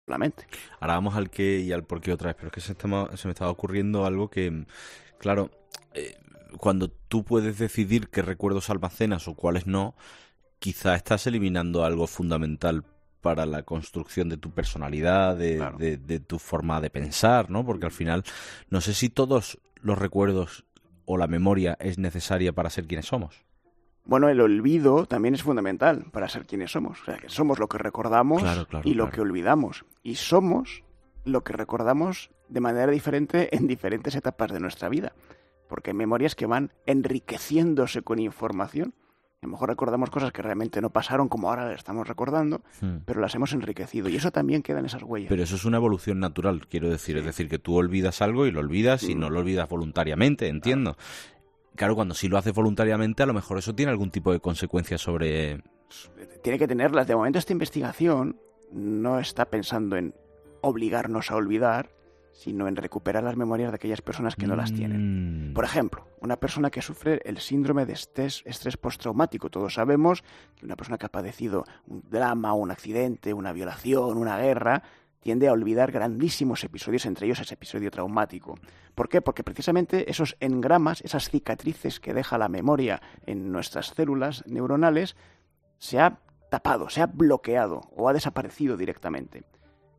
Divulgador de ciencia